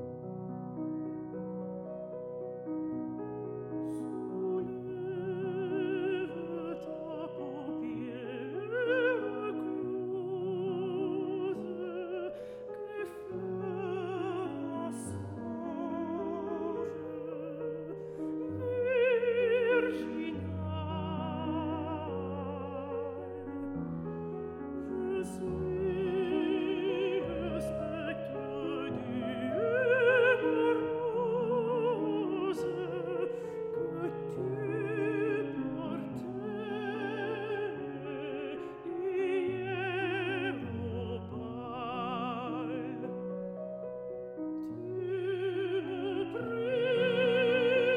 一場令人難忘的音樂會。
與生俱來且年輕的音質與體力，
使這位演唱家能夠充分控制、並相當細緻的處理每段語句與表情。
在2000年伊莉莎白女王音樂大賽的獲獎音樂會實況，